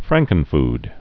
(frăngkən-fd)